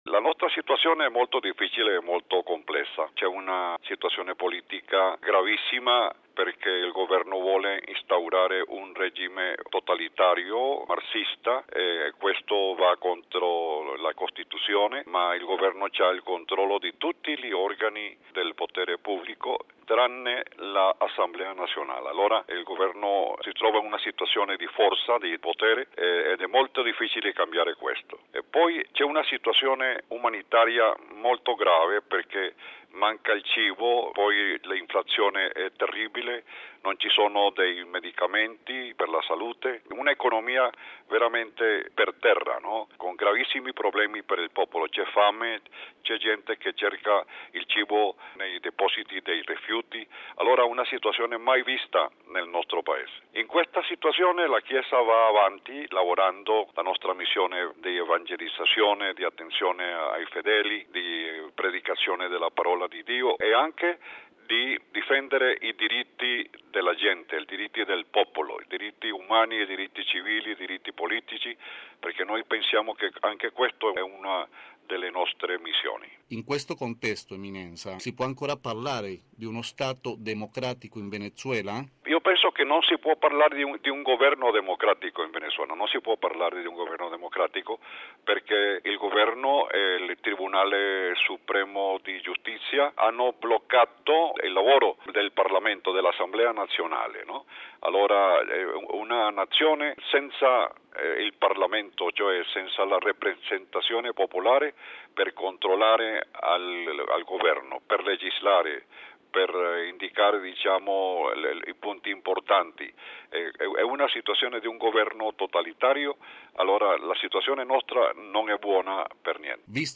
Anche la Chiesa denuncia con forza le violazioni dei diritti umani e reclama il ripristino delle istituzioni democratiche. Ascoltiamo il cardinale Jorge Urosa Savino, arcivescovo di Caracas